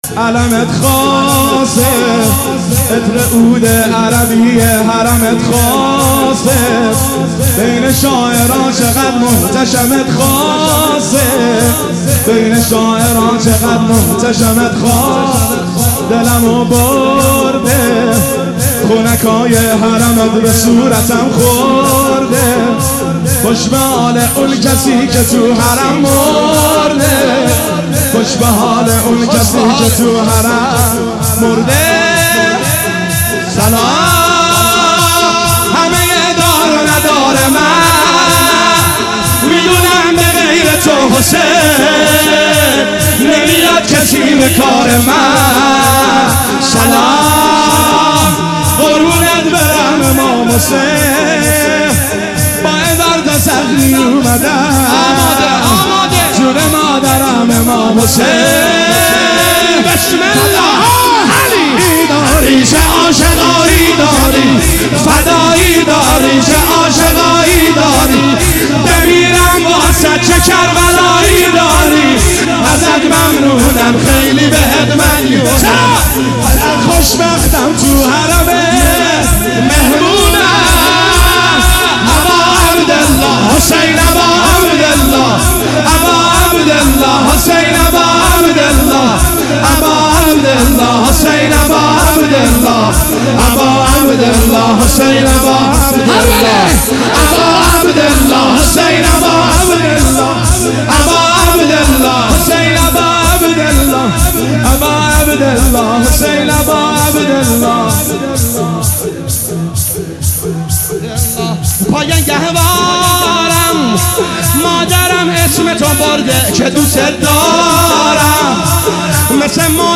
شور شام شهادت حضرت معصومه (س) 1402